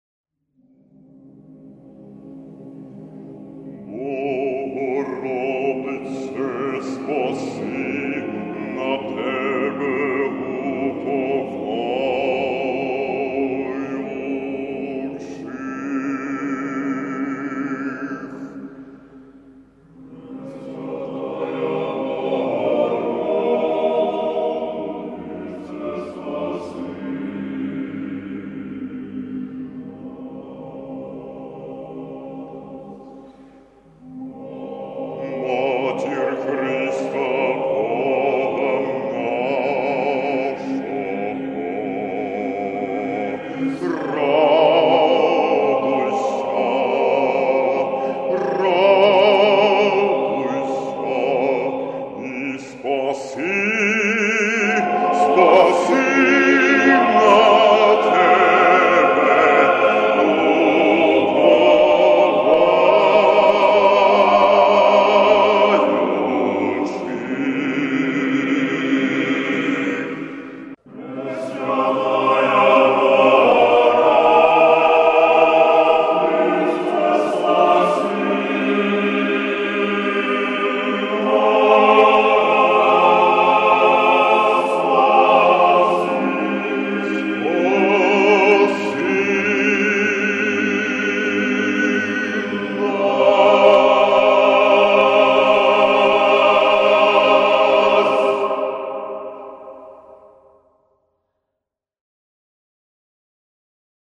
Привет всем от баса-профундо с Украины!Greetings to all from the bass - profundo from Ukraine!
чоловічий ансамбль Запорізькі козаки